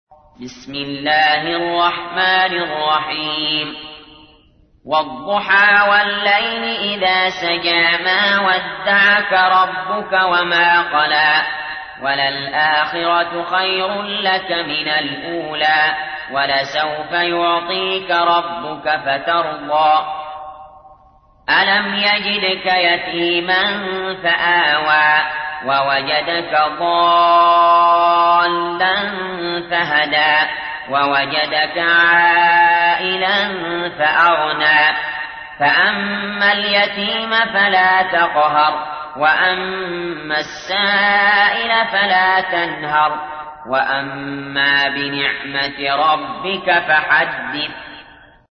تحميل : 93. سورة الضحى / القارئ علي جابر / القرآن الكريم / موقع يا حسين